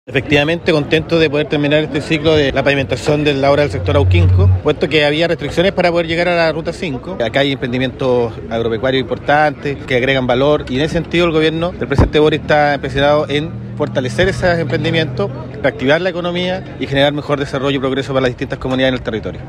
La ceremonia se desarrolló este miércoles con la presencia de autoridades regionales y locales.
Delegado-Jorge-Alvial.mp3